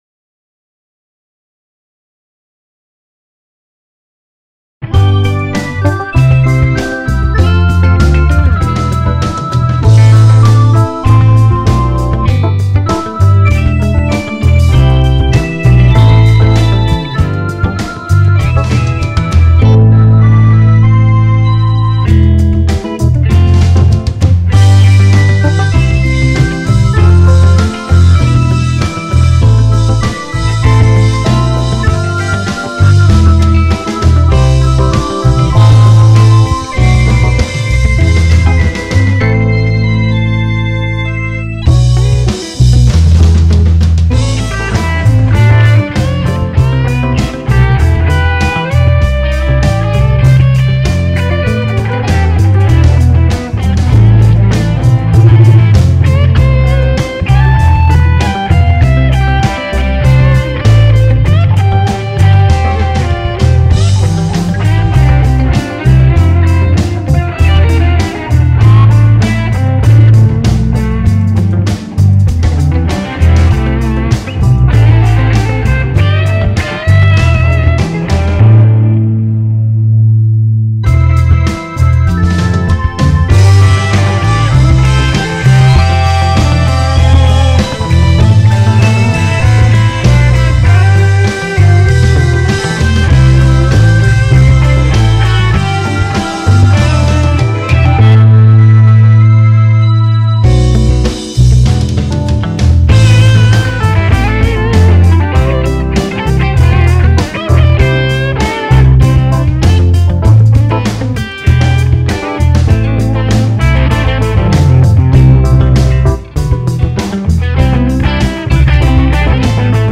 • Жанр: Джаз-рок